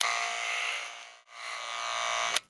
haircut6.wav